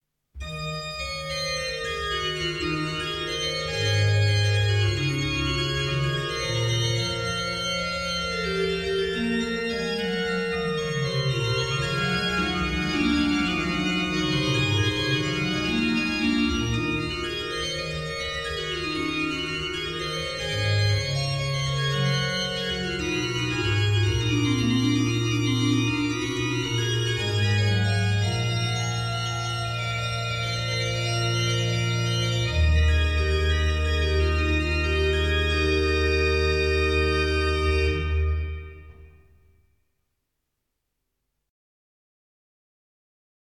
Kostol sv. Jána Krstiteľa
Organový pozitív I / 6